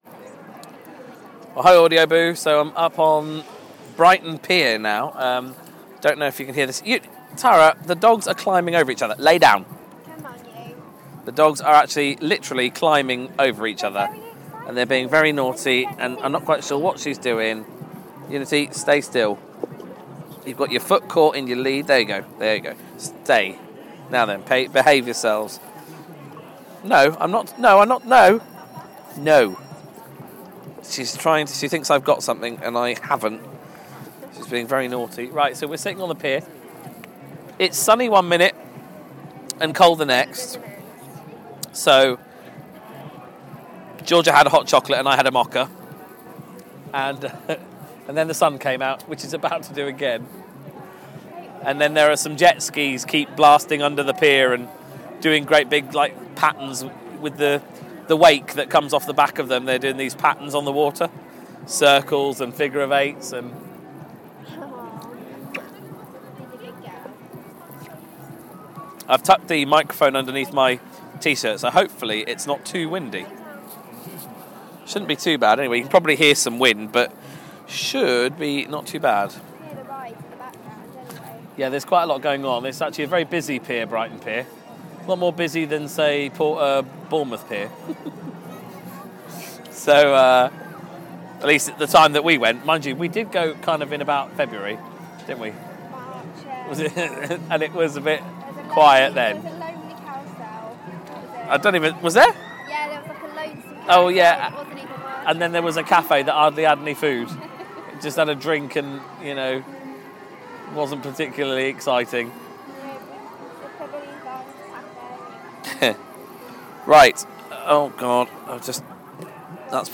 On Brighton Pier looking over the waves.